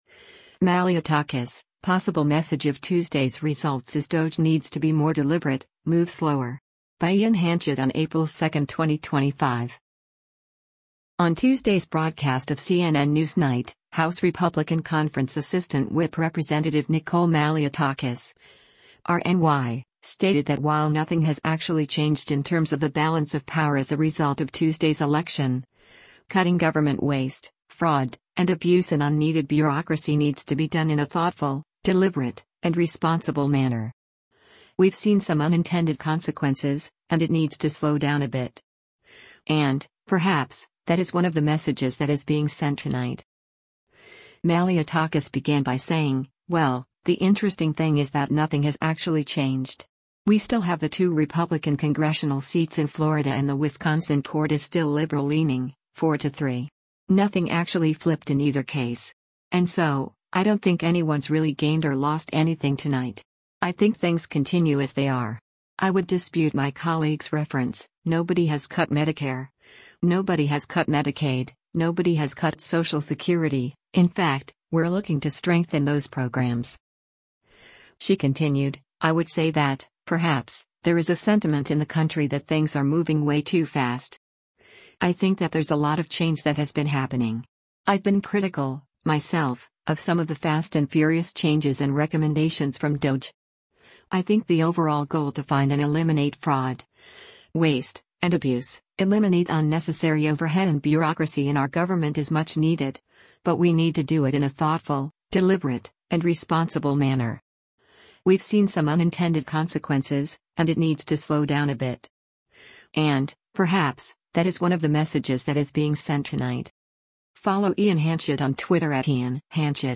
On Tuesday’s broadcast of “CNN NewsNight,” House Republican Conference Assistant Whip Rep. Nicole Malliotakis (R-NY) stated that while nothing has actually changed in terms of the balance of power as a result of Tuesday’s election, cutting government waste, fraud, and abuse and unneeded bureaucracy needs to be done “in a thoughtful, deliberate, and responsible manner.